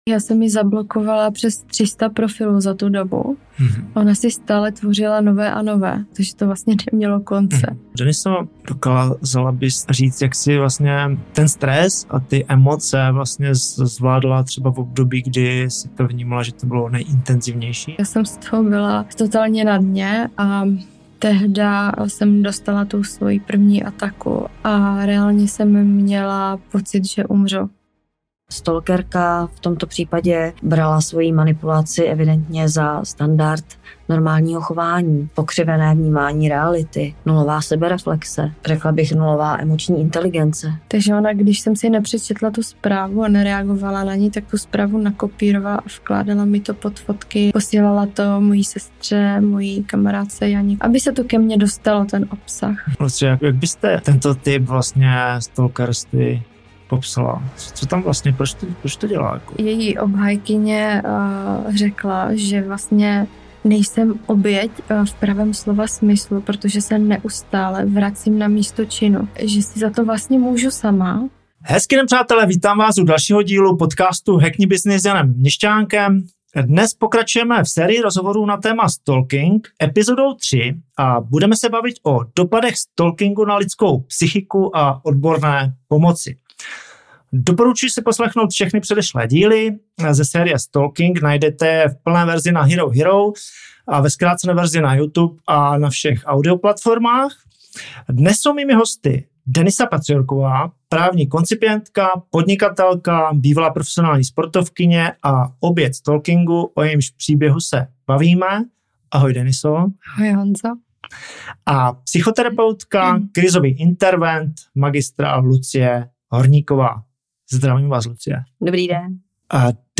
série 5 rozhovorů